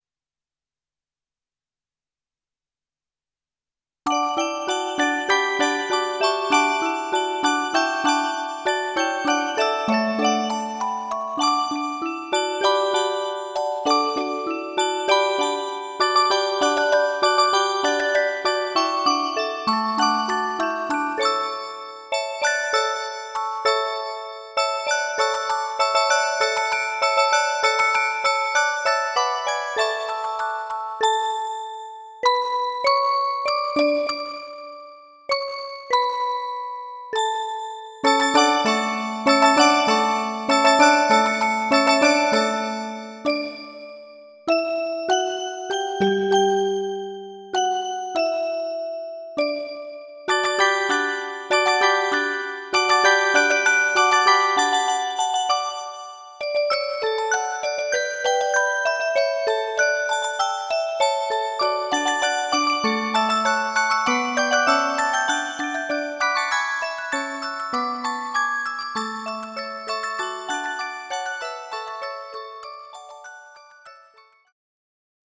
MUSIC BOX COLLECTION
オルゴールの音色でＨｏｌｌｙなクリスマスを・・・。
キラキラと輝くようなオルゴールの音色があなたのクリスマスを彩ります。